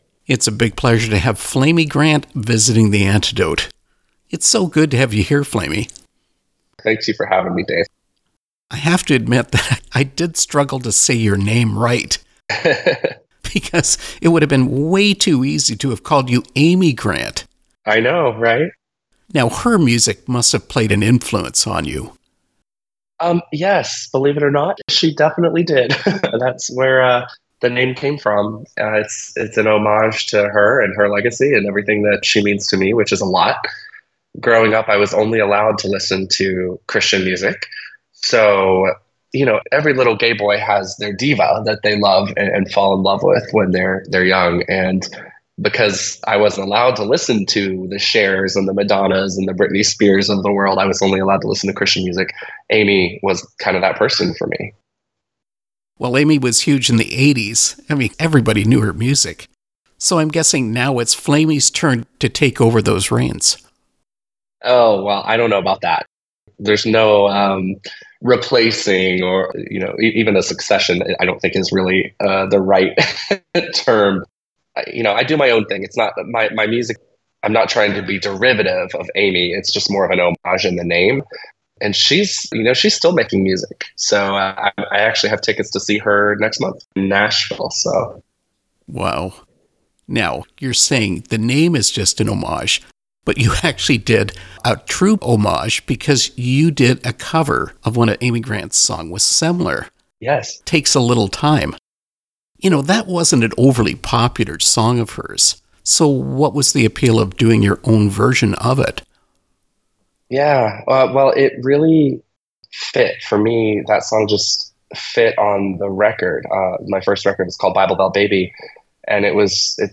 Interview with Flamy Grant
flamy-grant-interview.mp3